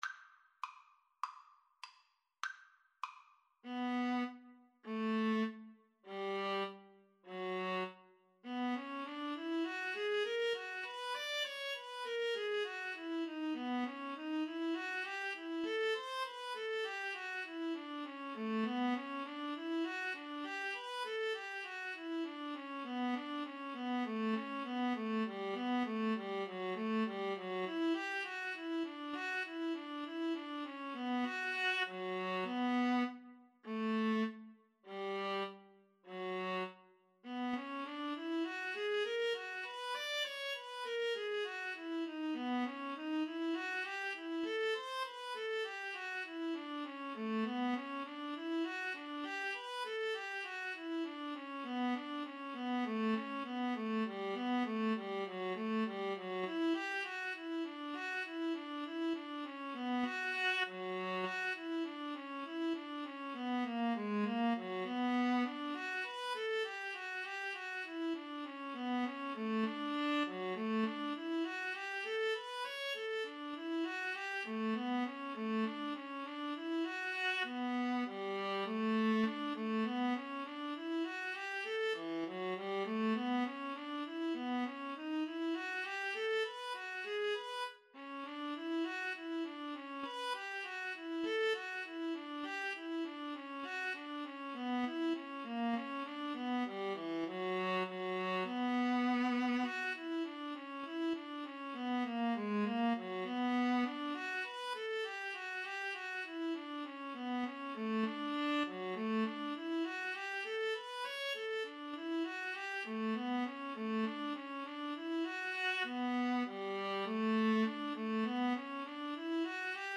B minor (Sounding Pitch) (View more B minor Music for Viola Duet )
2/2 (View more 2/2 Music)
Viola Duet  (View more Intermediate Viola Duet Music)
Classical (View more Classical Viola Duet Music)